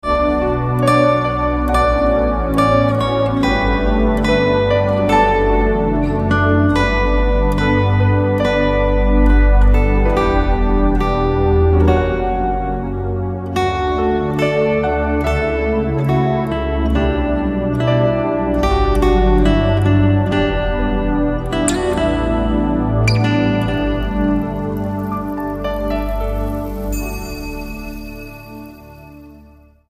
STYLE: MOR / Soft Pop
A relaxing and soothing selection of 14 instrumental tracks
acoustic and electric guitars